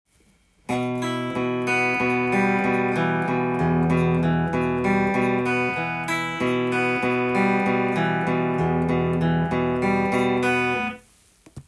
５弦強化
５弦強化のアルペジオパターンでは
コードEmを押さえながら必ず５弦をピッキングして
１弦から順番にアルペジオしていきます。